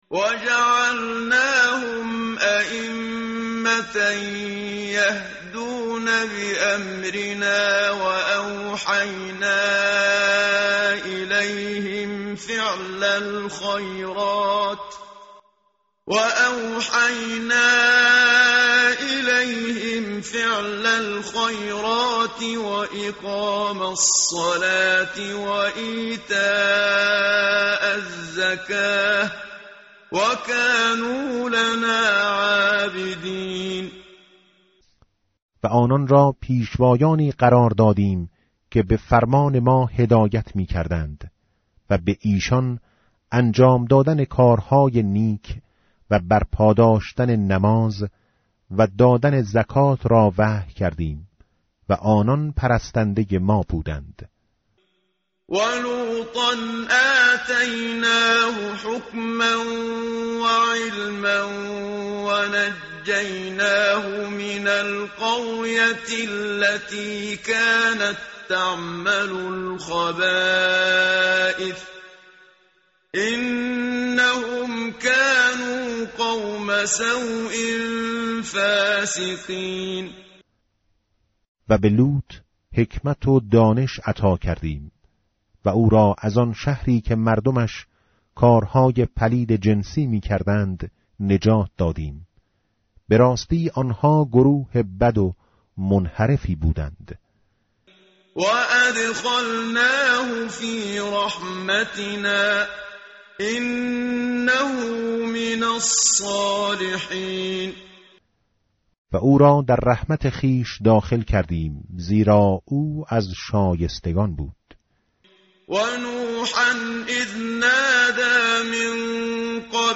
tartil_menshavi va tarjome_Page_328.mp3